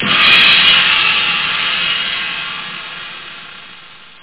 Amiga 8-bit Sampled Voice
1 channel
sr-16_crashcymbal.mp3